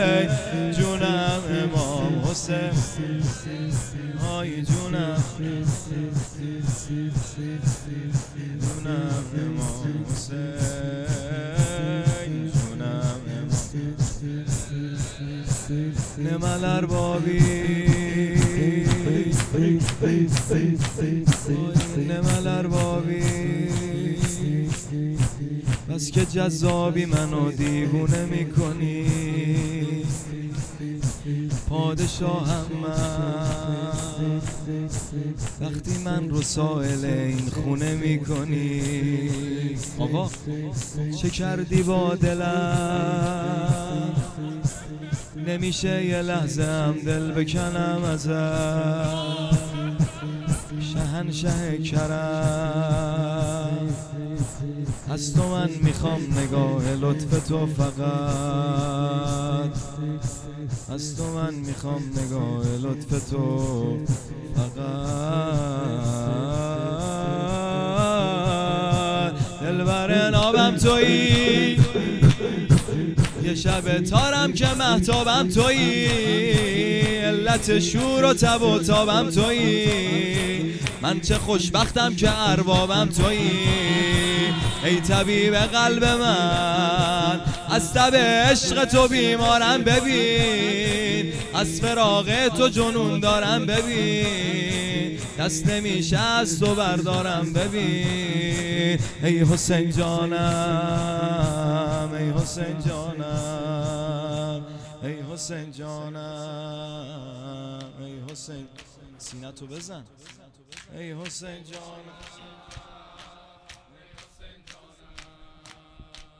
شور احساسی _ دلبر نابم تویی